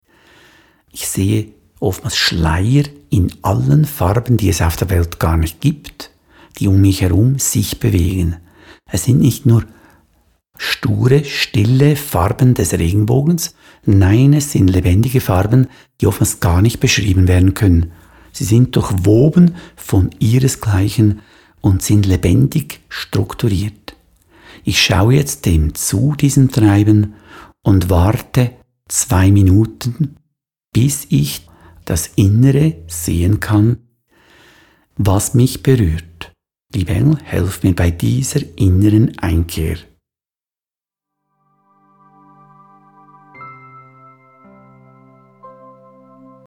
Diese mp3-Download-Datei enthält Meditationen und Seelenreisen